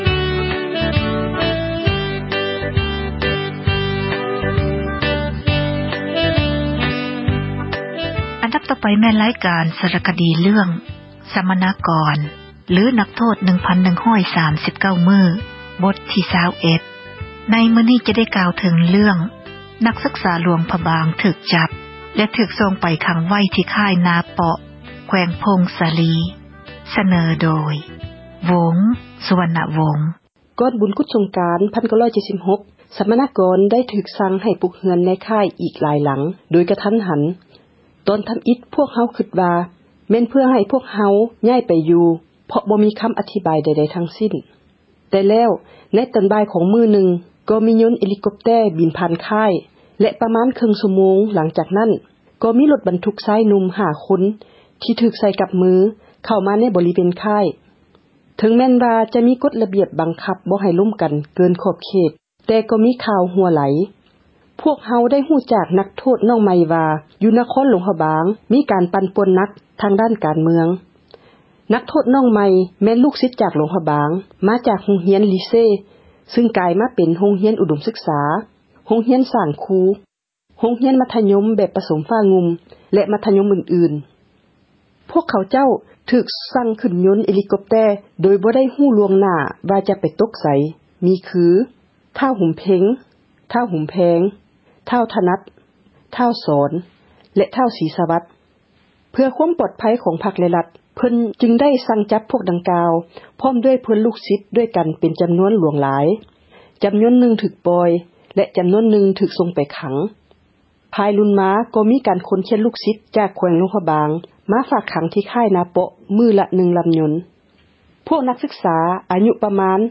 ສາຣະຄະດີ ເຣື້ອງ ສັມມະນາກອນ ຫຼື ນັກໂທດ 1139 ມື້, ບົດທີ 21 ໃນມື້ນີ້ ຈະກ່າວເຖິງ  ນັກສືກສາ ຫຼວງພຣະບາງ ຖືກຈັບ ແລະ ຖືກ ສົ່ງໄປ ຂັງໄວ້ ທີ່ຄ້າຍ ນາເປາະ ແຂວງ ຜົ້ງສາລີ.